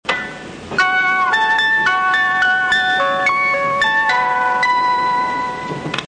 車内チャイム
旧チャイムのオルゴールは各乗務員室に何れか１曲がランダムに搭載されていました。